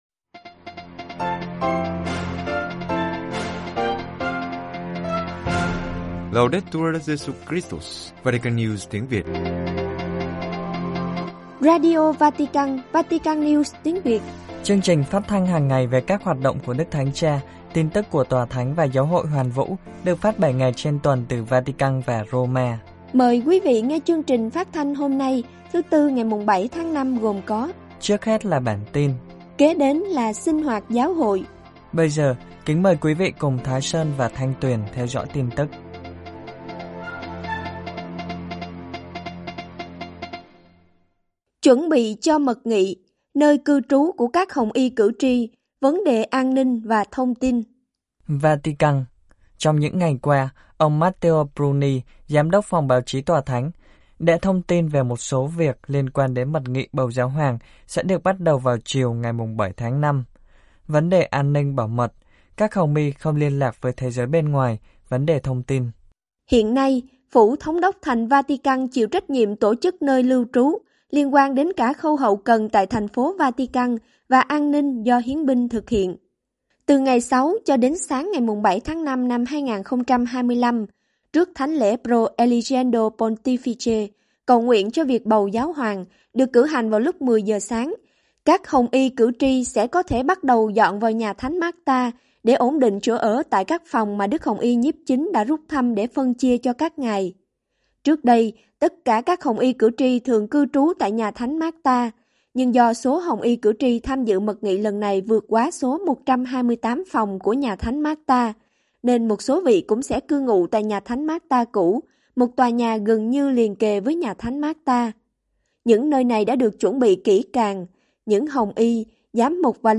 Chương trình phát thanh tiếng Việt của Vatican News phát mỗi ngày 25 phút, gồm các mục chính như: Tin tức, Sinh hoạt Giáo Hội, Gặp Đức Giáo Hoàng, Gương chứng nhân, Suy niệm Lời Chúa, Bình luận, Giáo hoàng và người trẻ, Phút cầu nguyện...